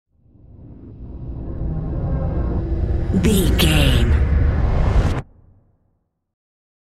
Sound Effects
In-crescendo
Atonal
Fast
ominous
eerie
synthesiser
ambience
pads